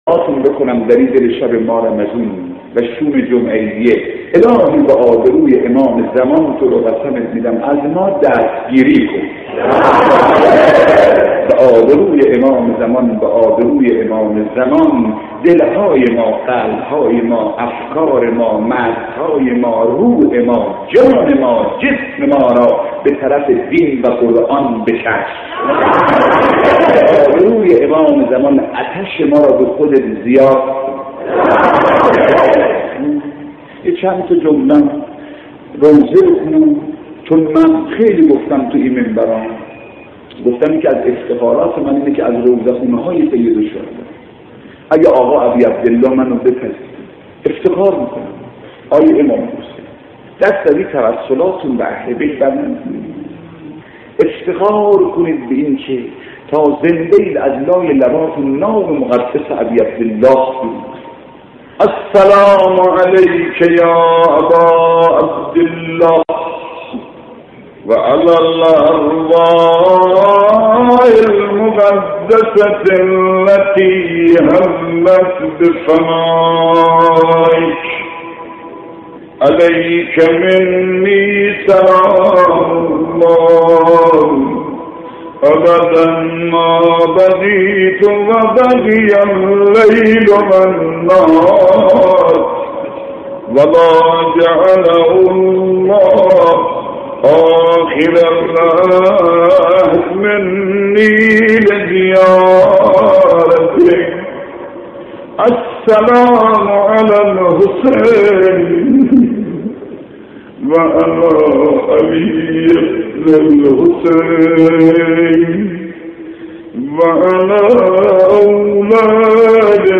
صوت/روضه‌ ماندگار مرحوم کافی برای امام حسین(ع)